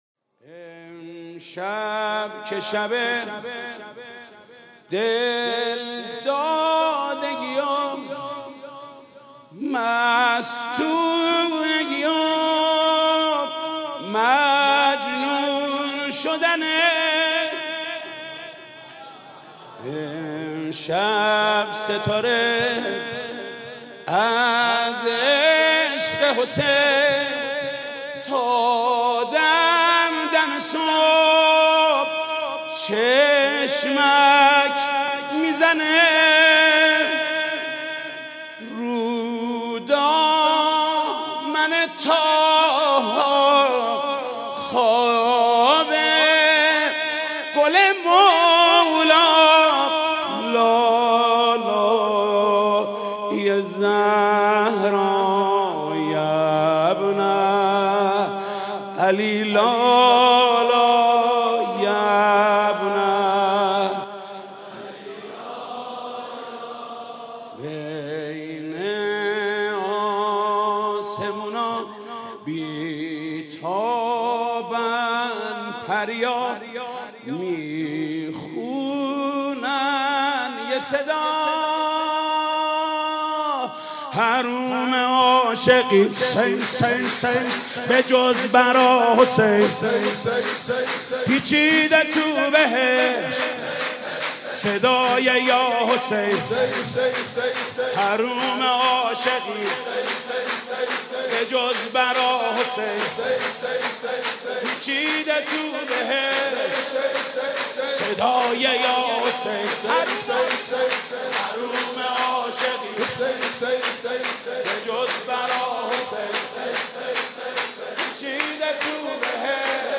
سرود: حرومه عاشقی به جز برا حسین